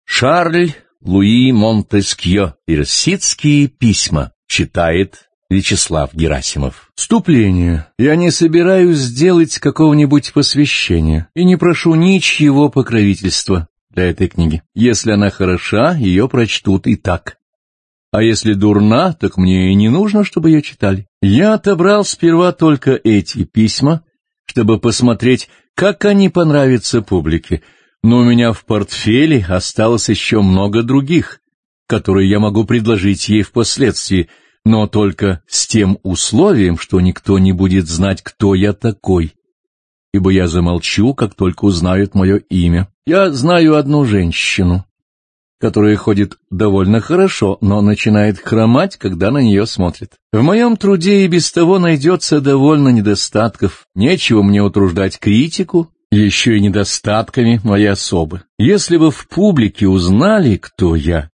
Аудиокнига Персидские письма | Библиотека аудиокниг
Aудиокнига Персидские письма Автор Шарль Луи Монтескьё